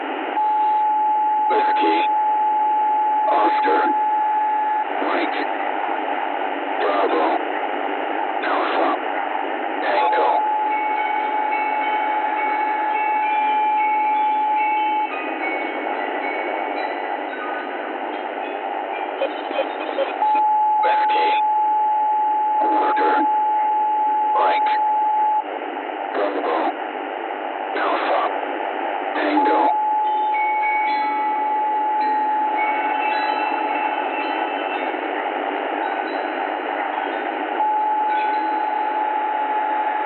radio_transmission_wombat.ogg